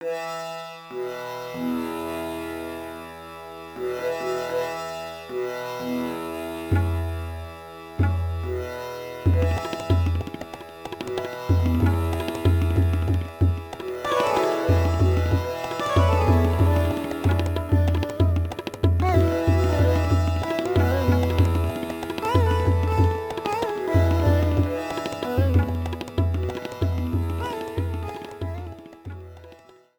Cropped to 30 seconds, fade-out added